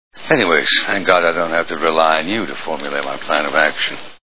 Deadwood TV Show Sound Bites